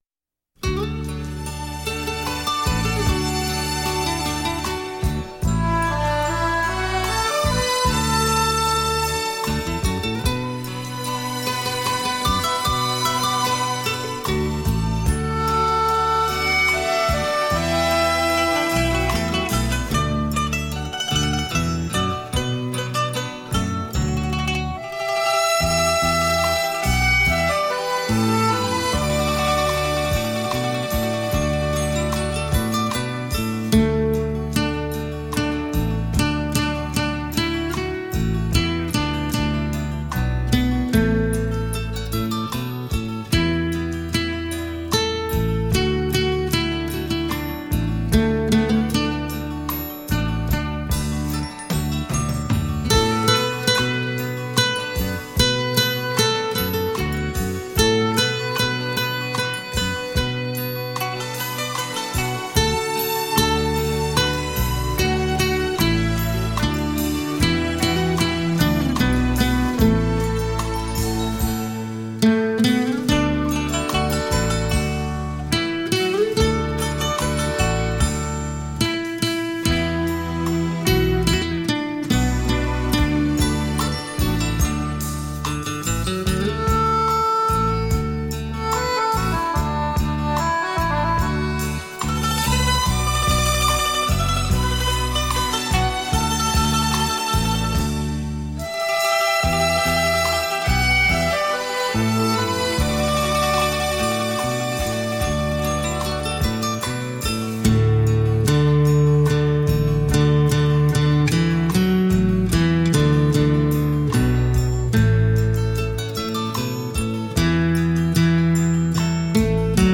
曲调优美 节奏清新
以吉它为主奏和小乐队伴奏的形式